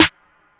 BWB [WAVE 2] SNARE (18).wav